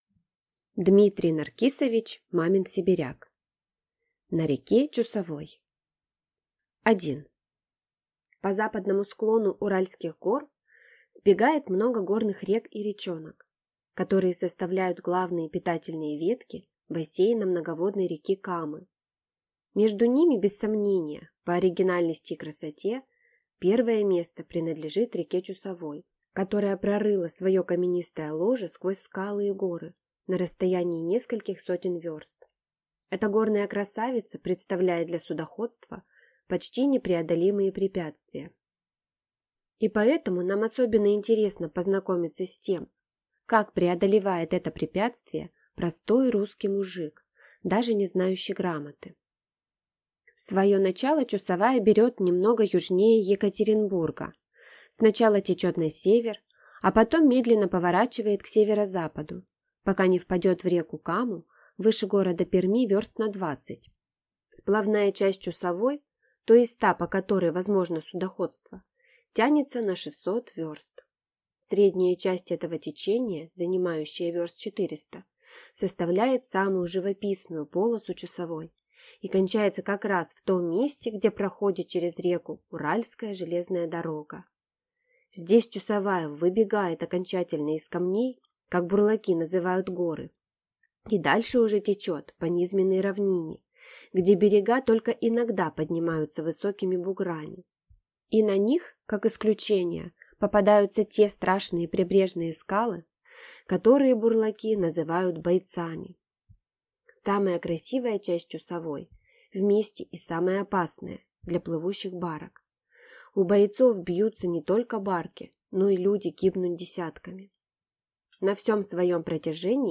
Аудиокнига На реке Чусовой | Библиотека аудиокниг